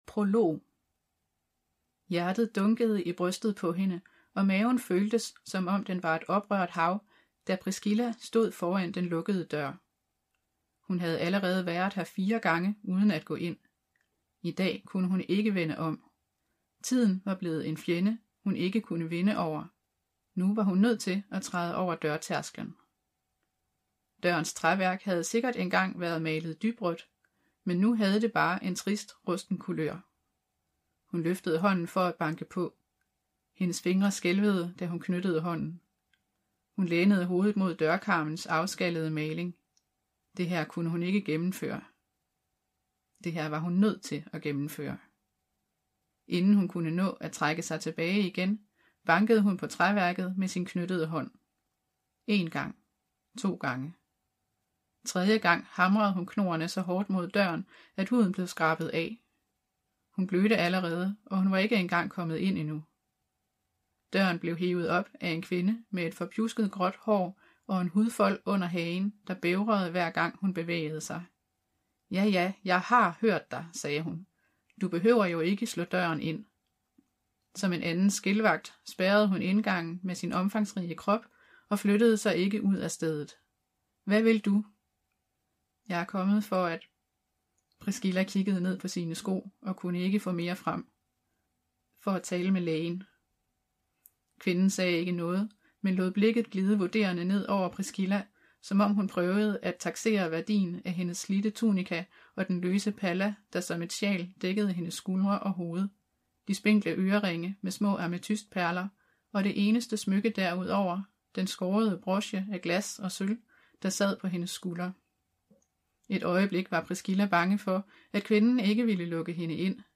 Hør et uddrag af Håbets port Håbets port Forfatter Tessa Afshar Bog Lydbog 249,95 kr.